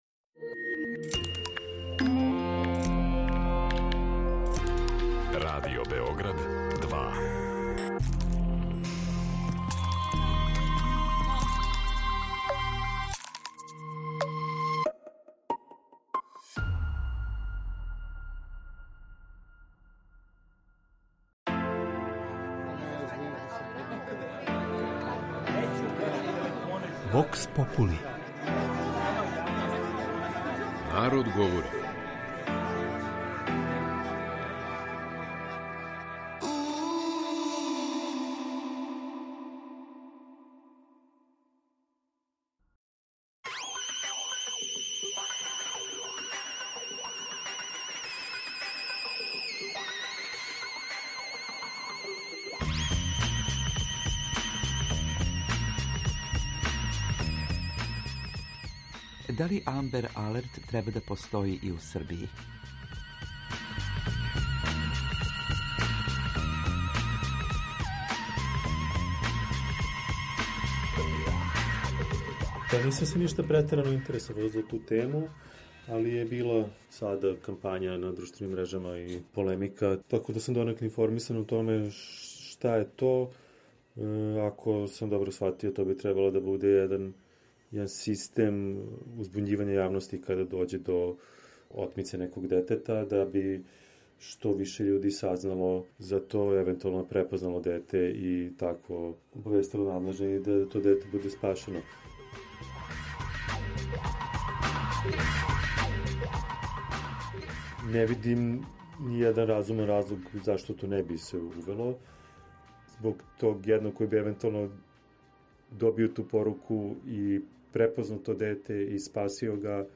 У данашњој емисији поразговарали смо са нашим суграђанима шта они мисле о идеји да Амбер алерт систем - специфичан модел ширења и објављивања информација у јавности када је реч о случајевима нестале деце - почне да се примењује и код нас.
Вокс попули